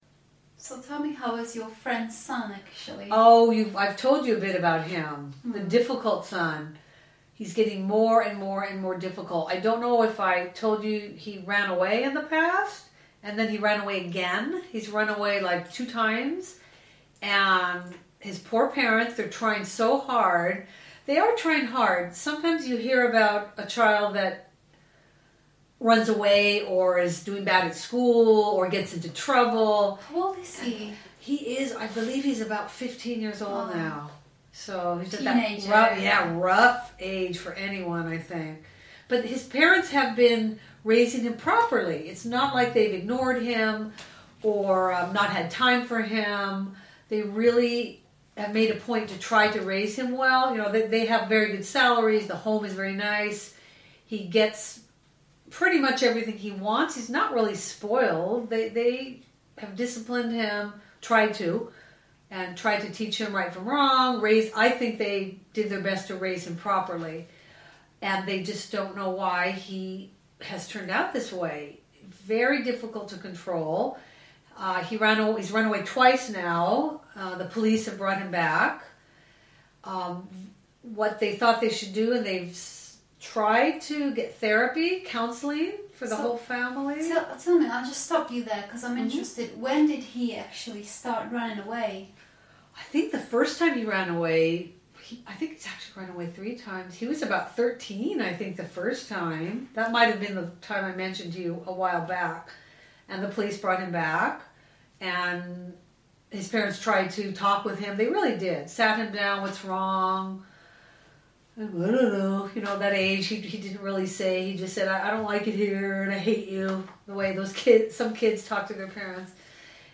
Ukázka z knihy
Polish Your Rusty English - Listening Practice 3 - unikátní a ojedinělá sbírka autentických rozhovorů anglických rodilých mluvčích.